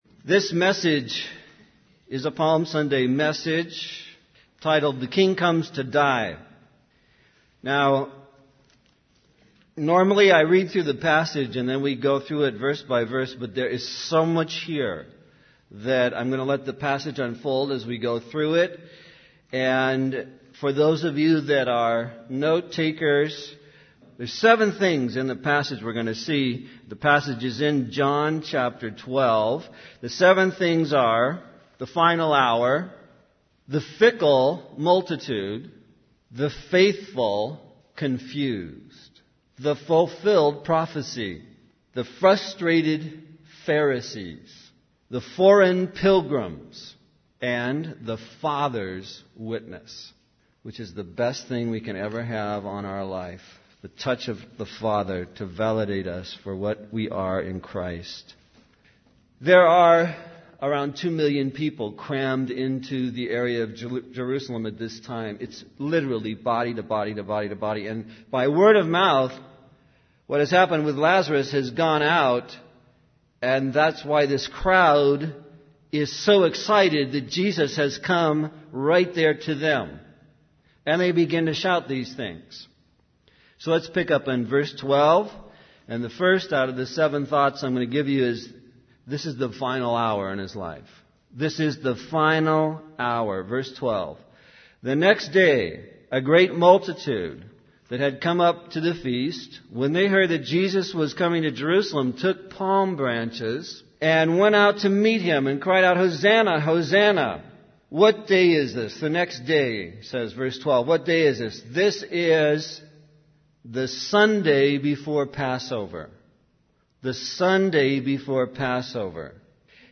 In this sermon titled 'The King Comes to Die,' the preacher focuses on the passage in John chapter 12. He highlights seven key elements in the passage: the final hour, the fickle multitude, the faithful confused, the fulfilled prophecy, the frustrated Pharisees, the foreign pilgrims, and the Father's witness.